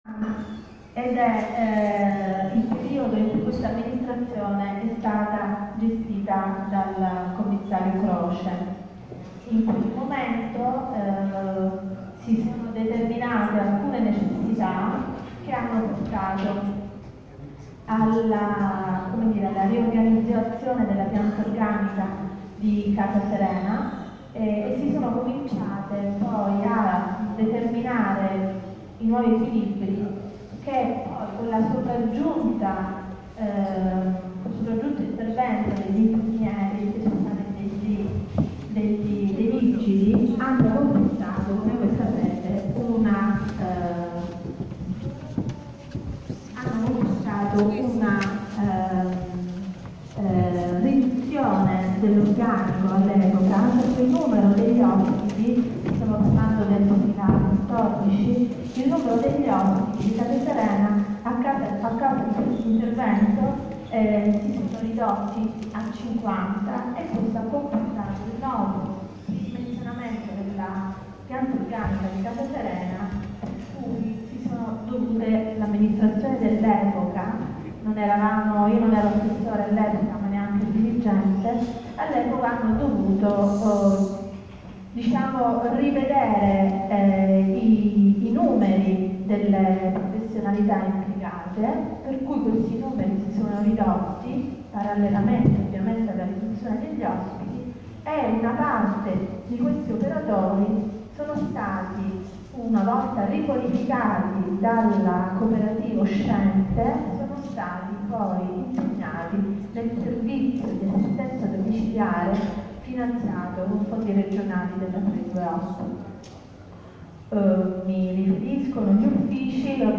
DURANTE LA SUA PARTECIPAZIONE ODIERNA, ALLA VI^ COMMISSIONE